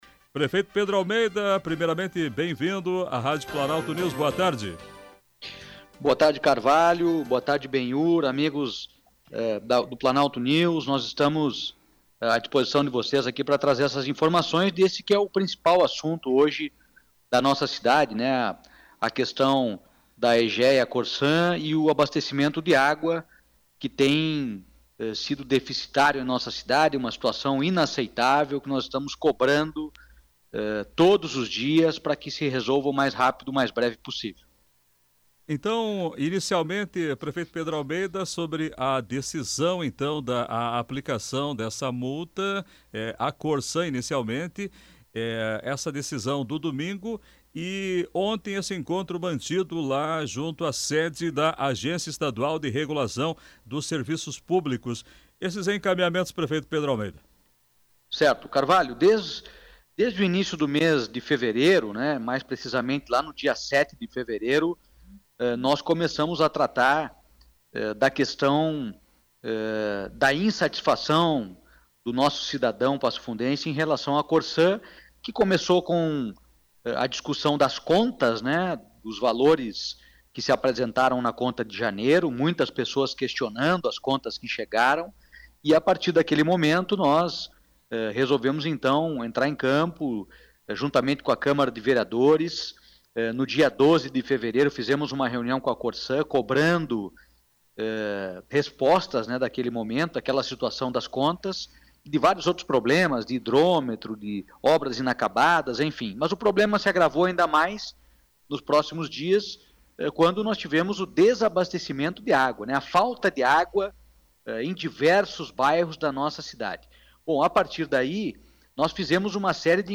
Em entrevista à Planalto News, prefeito Pedro Almeida fala das ações para solução imediata do abastecimento de água
Na tarde desta quarta-feira (12), o prefeito Pedro Almeida concedeu entrevista à Rádio Planalto News (92.1), emissora do Grupo Planalto de Comunicação, sobre os encaminhamentos buscando uma solução para o problema que mais traz preocupação à população no momento, que é o abastecimento de água em Passo Fundo. O chefe do Executivo destacou que está cobrando uma solução imediata para o problema, a fim que a distribuição esteja normalizada em toda a cidade.